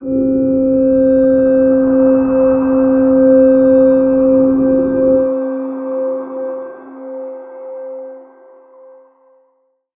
G_Crystal-C5-f.wav